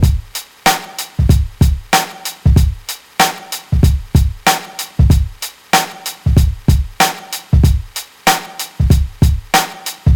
• 95 Bpm Old School Rap Drum Loop C# Key.wav
Free drum beat - kick tuned to the C# note. Loudest frequency: 1770Hz
95-bpm-old-school-rap-drum-loop-c-sharp-key-tXx.wav